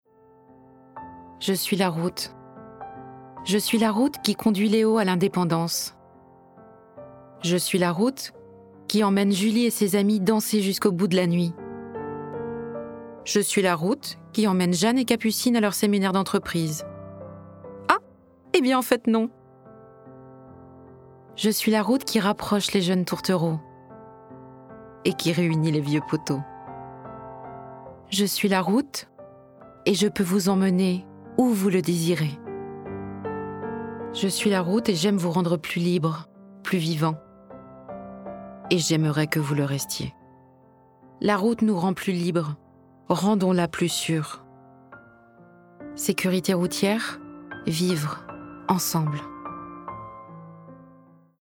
Ma voix est celle d’une femme entre trente et quarante ans qui a du coeur, de l’humanité, de la luminosité et une douceur qui convient par exemple très bien à la narration d’un drame historique.
Publicité institutionnelle de prévention routière : La route nous rend plus libres, à nous de la rendre plus sûre.